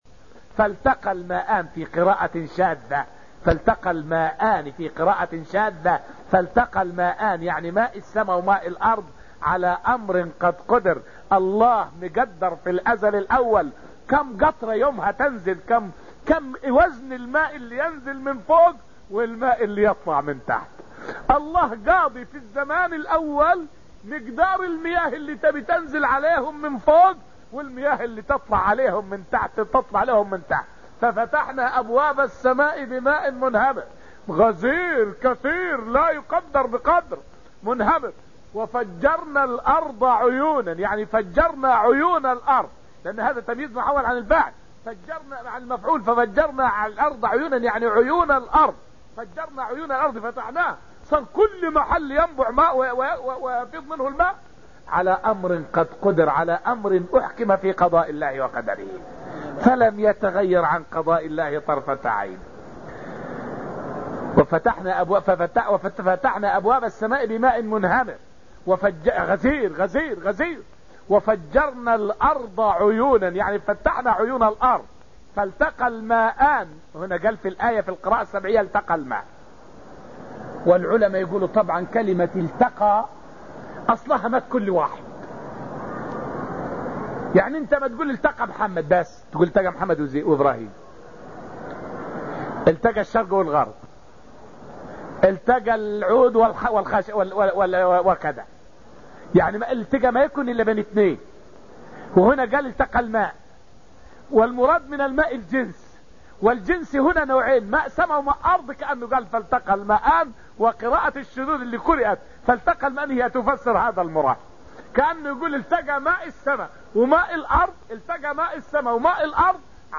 فائدة من الدرس الرابع من دروس تفسير سورة القمر والتي ألقيت في المسجد النبوي الشريف حول معنى قوله تعالى {فالتقى الماء على أمر قد قدر}.